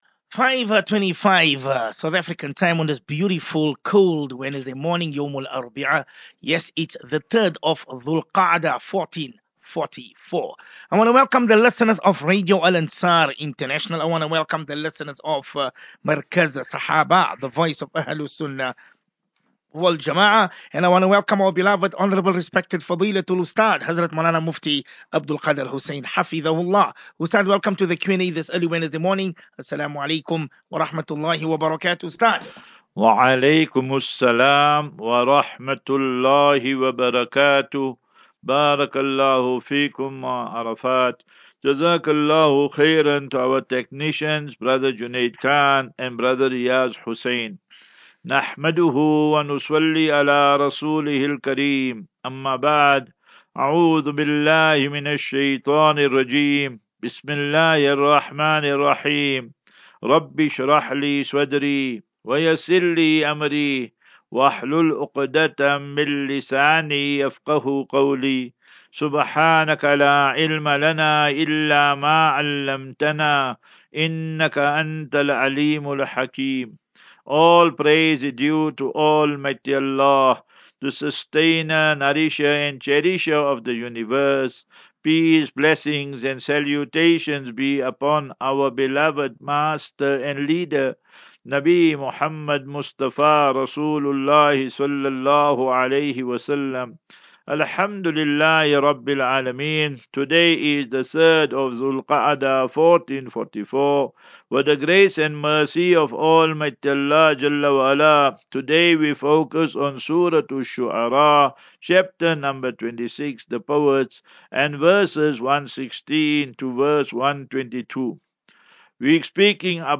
As Safinatu Ilal Jannah Naseeha and Q and A 24 May 24 May 23 Assafinatu